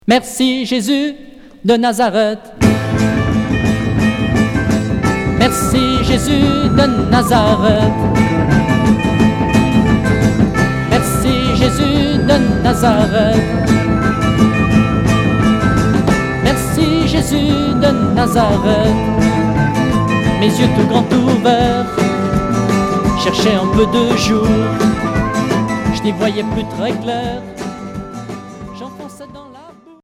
Folk rock religieux Unique EP retour à l'accueil